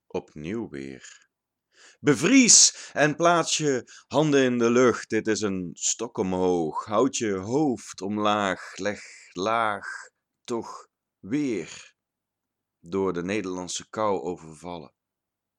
Poëzie